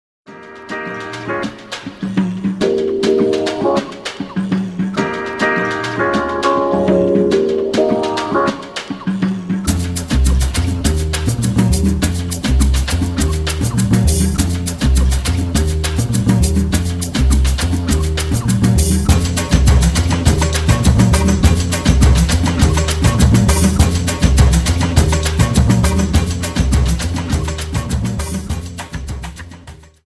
Dance: Samba Song